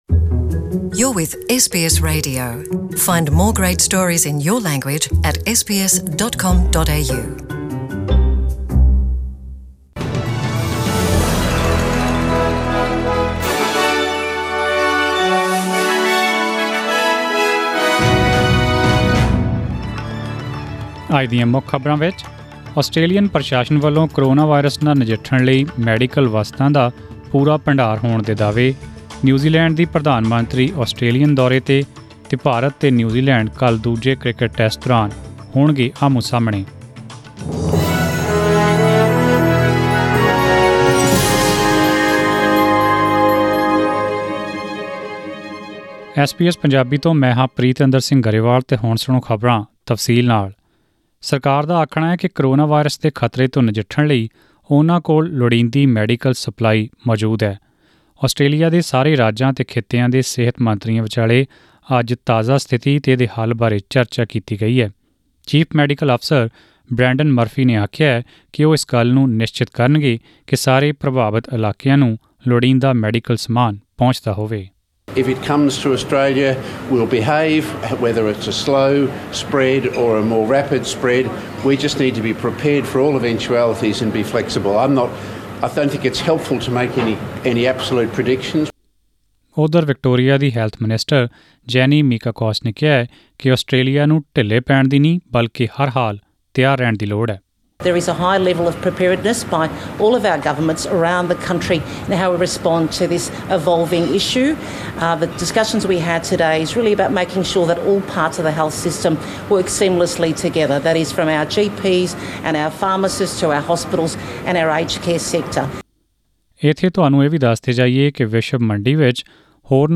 Australian News in Punjabi: 28 February 2020